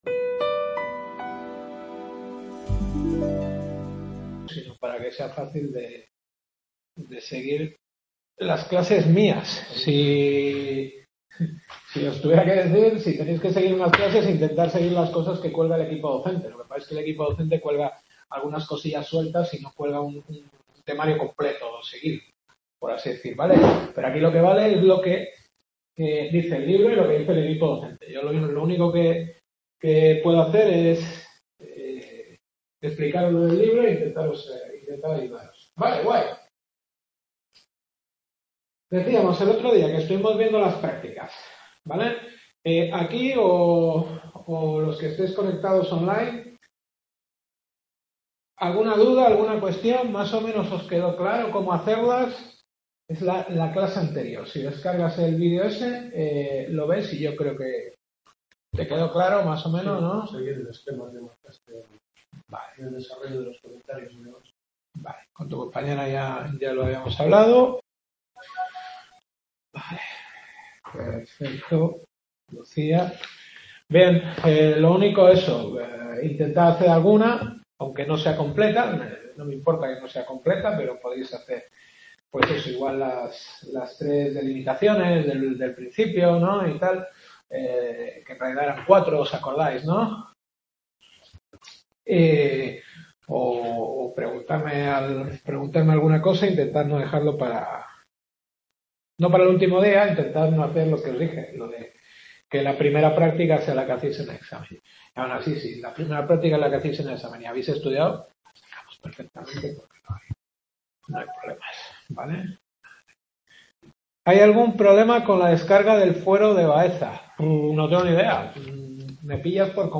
Historia del Derecho. Séptima Clase.